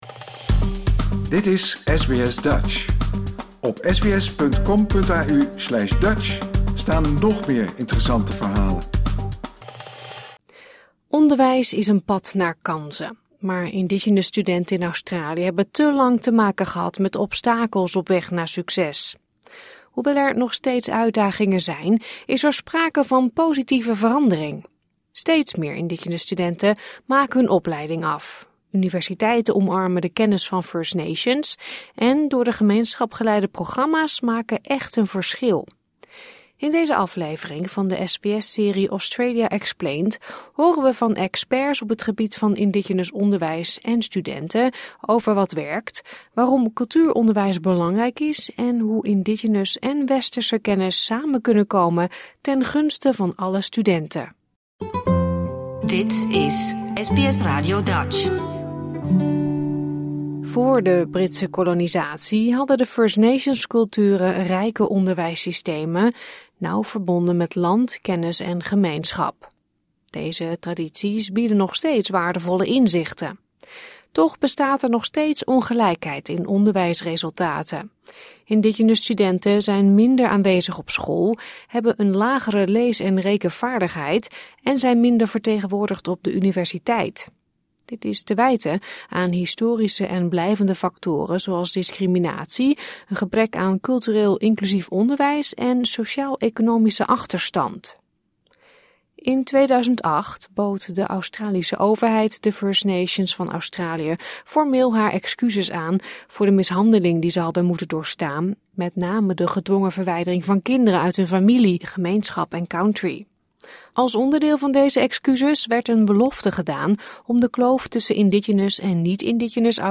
In deze aflevering van Australia Explained horen we van experts op het gebied van Indigenous onderwijs en studenten over wat werkt, waarom cultuuronderwijs belangrijk is en hoe Indigenous en westerse kennis samen kunnen komen ten gunste van alle studenten.